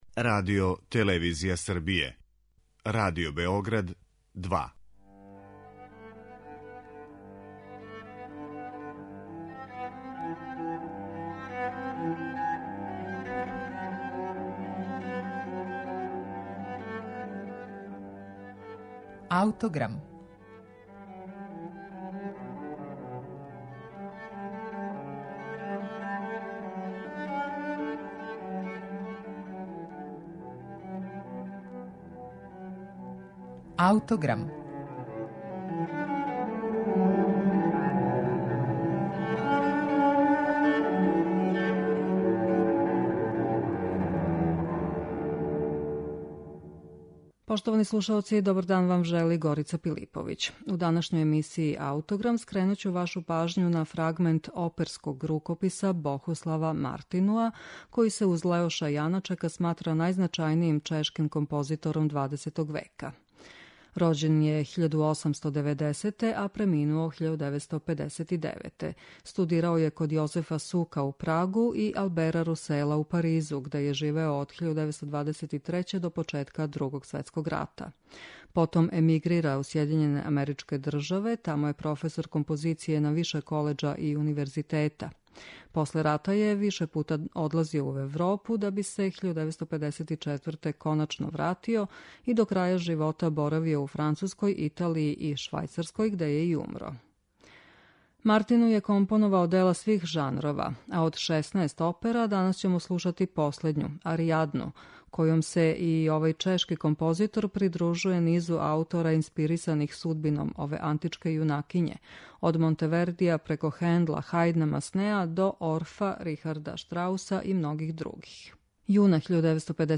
Опера